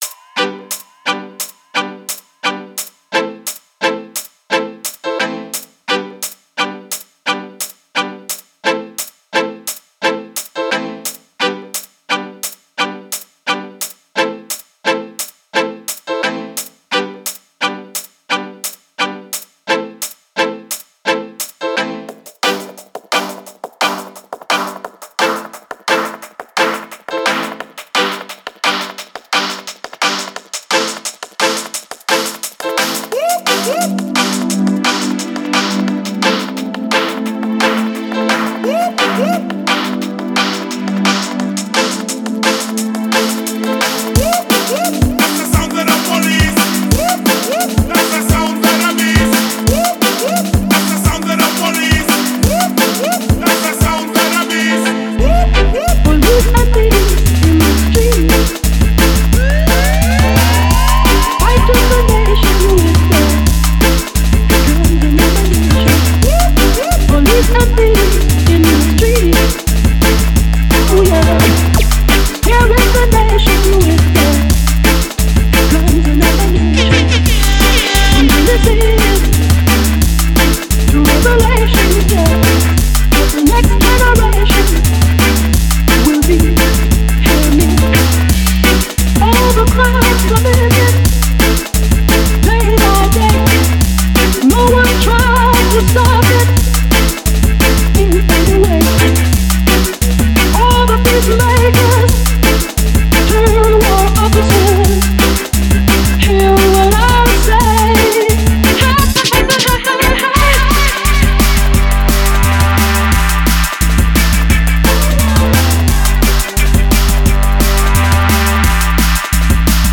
# rmx # Tag